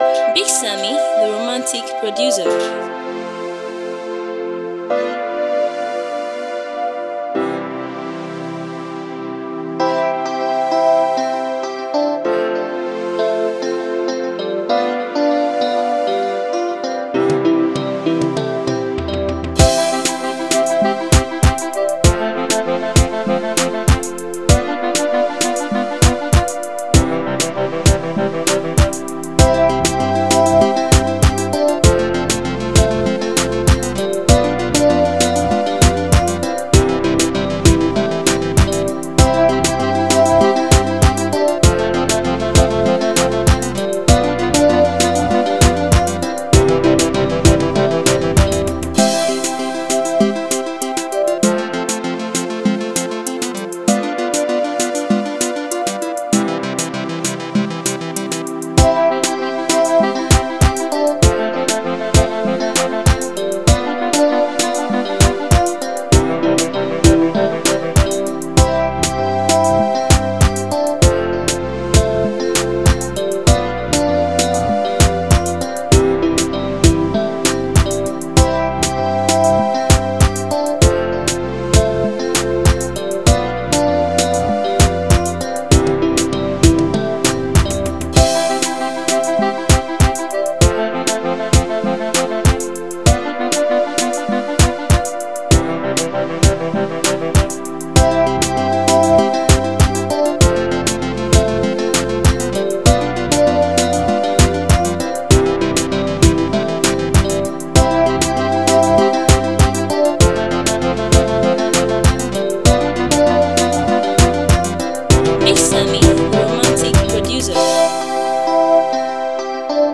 bringing a romantic and love-filled beat to the forefront.